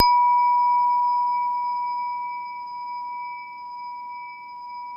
WHINE  B3 -L.wav